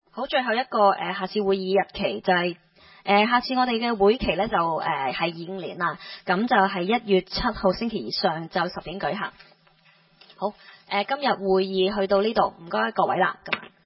區議會大會的錄音記錄
西貢將軍澳政府綜合大樓三樓